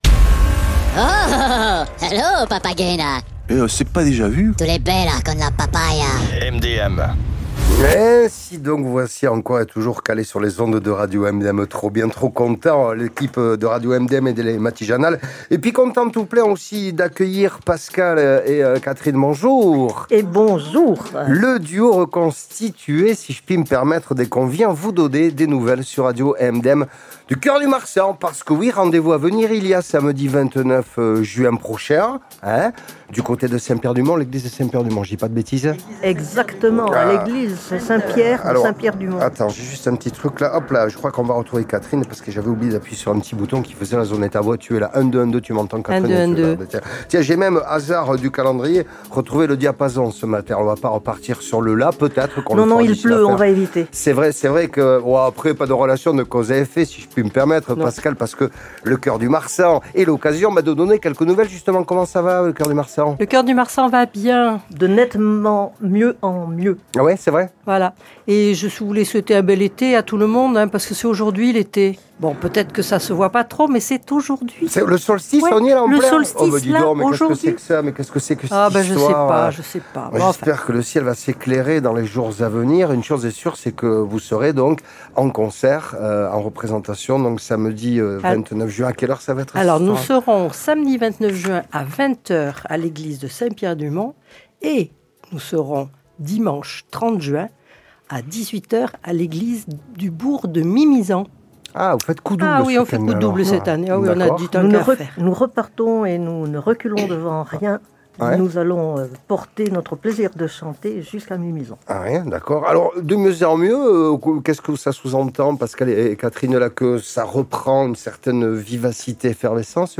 Musique Radio MdM